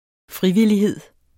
Udtale [ ˈfʁiviliˌheðˀ ]